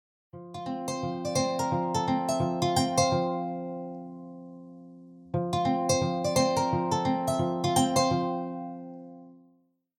Blues Ringtones